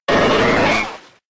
Cri de Tentacool K.O. dans Pokémon X et Y.